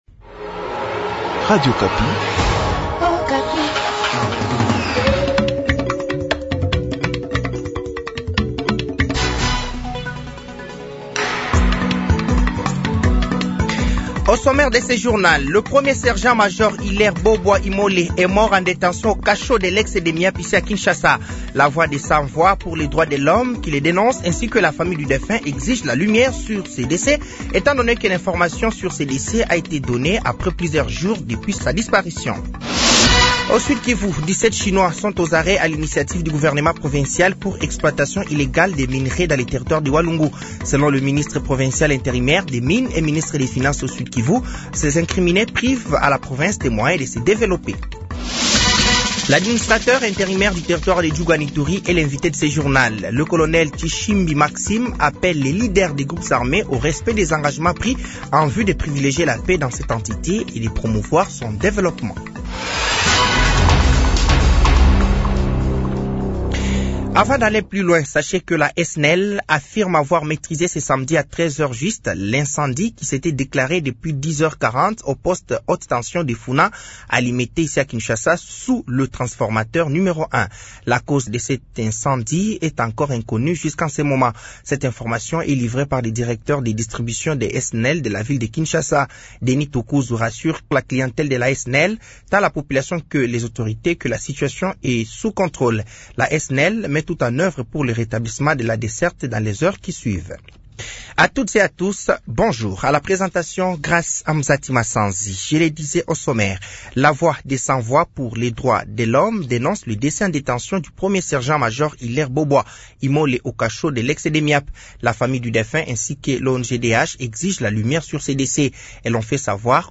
Journal français de 15h de ce samedi 21 décembre 2024